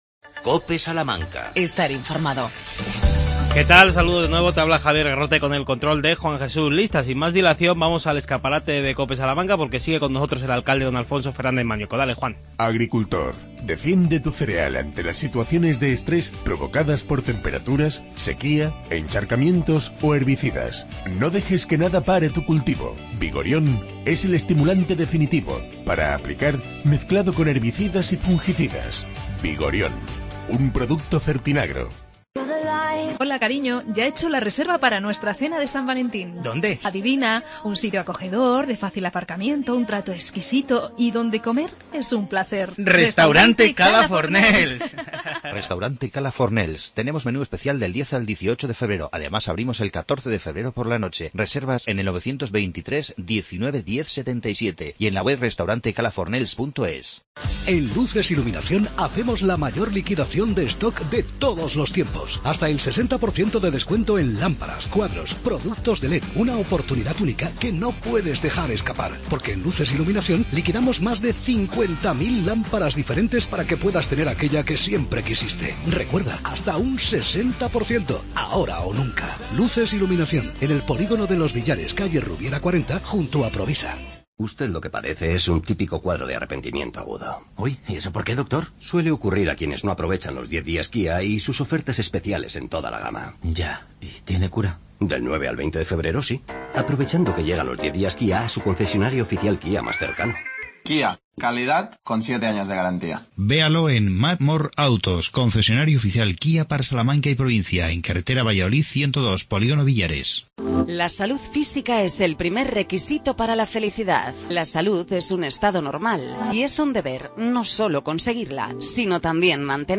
AUDIO: Segunda parte de la entrevista al alcalde de Salamanca Alfonso Fernández Mañueco.